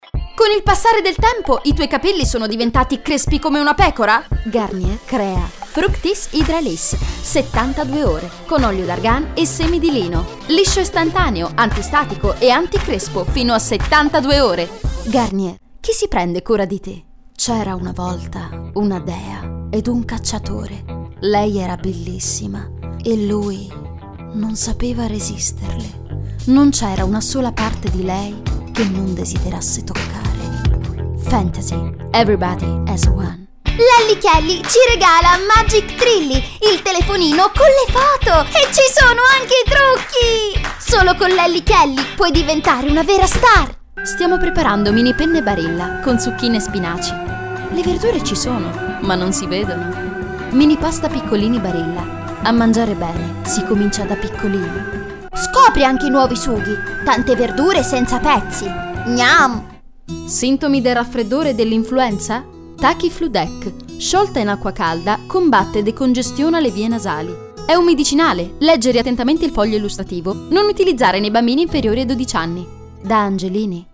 voce giovane, fresca, solare. Teenagers, bambini, ragazza.
Sprechprobe: eLearning (Muttersprache):